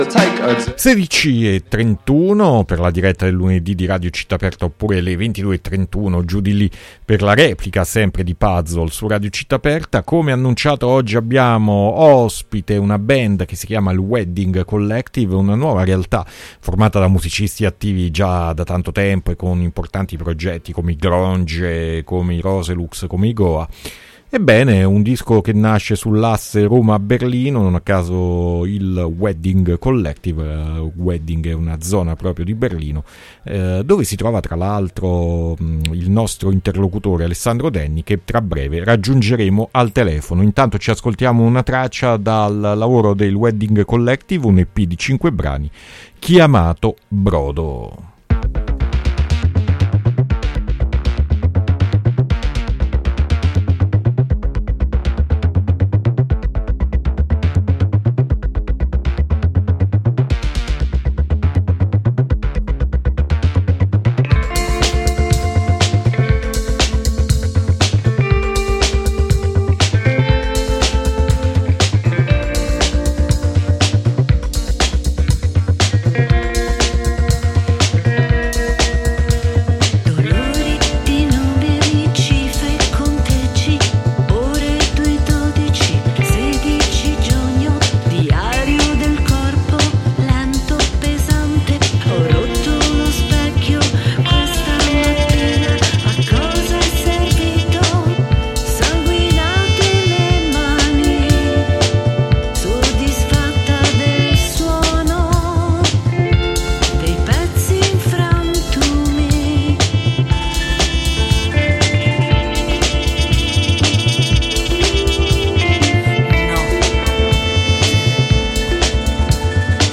Intervista Il Wedding Kollektiv
Ascolta l'intervista a Il Wedding Kollektiv, formazione nata sull'asse Roma-Berlino, ospite di Puzzle su Radio Città Aperta.